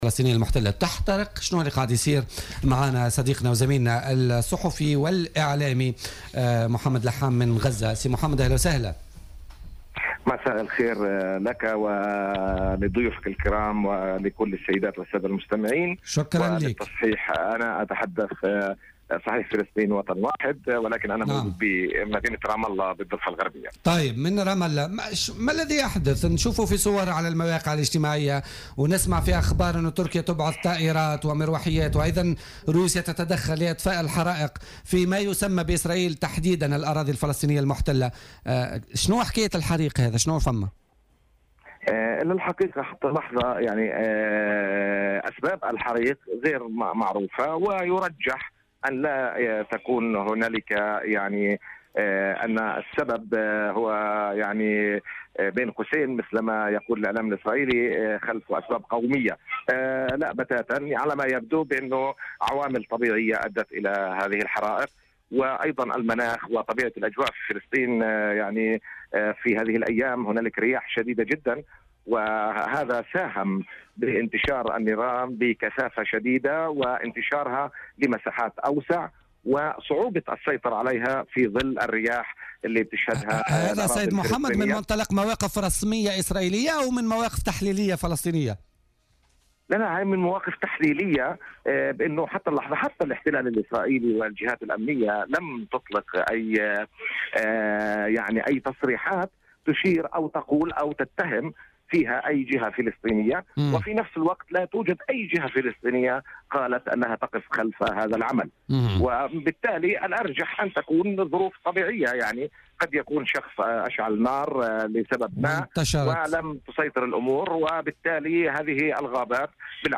في اتصال هاتفي من رام الله مع "الجوهرة أف أم" في برنامج "بوليتيكا"،